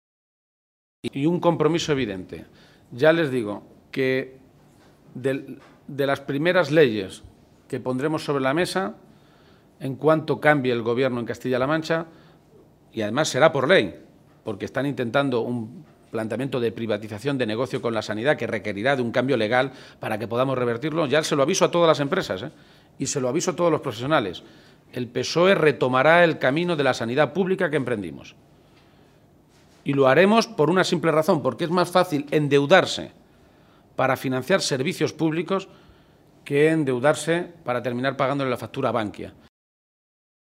El líder de los socialistas castellano-manchegos ha realizado estas declaraciones en un desayuno informativo en Ciudad Real, donde ha estado acompañado por el secretario provincial del PSOE en esta provincia, José Manuel Caballero, por la secretaria de Organización, Blanca Fernández, y por la portavoz regional, Cristina Maestre.